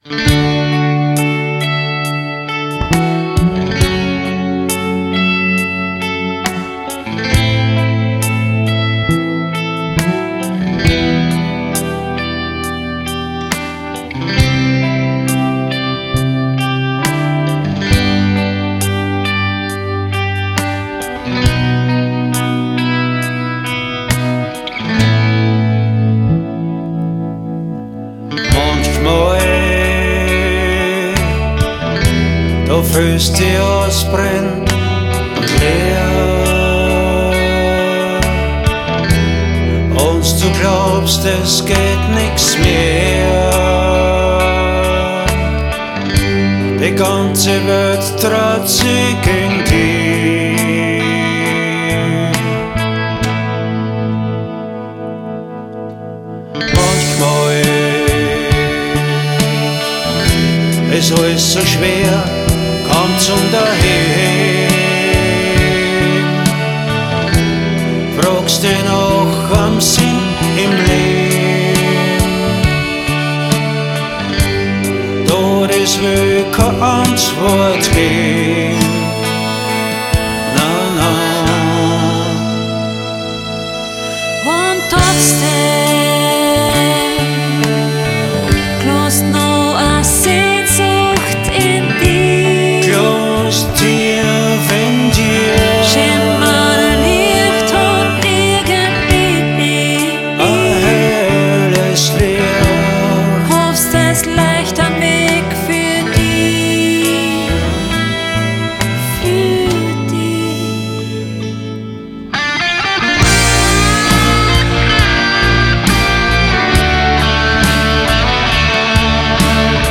fem-voc, harm
g,dr,syn